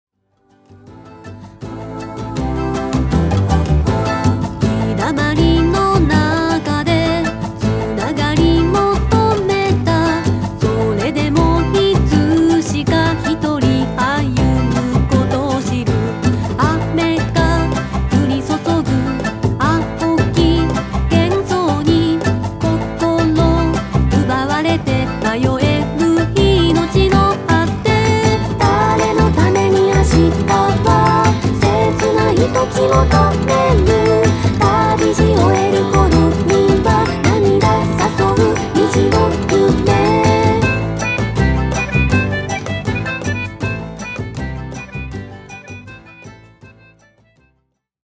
两首歌都是典型的异域风格，题材也类似。
两首歌里拍手的特效，口琴的音色，哼鸣的和声，手鼓的应用非常吸引人，一种远离文明的特色。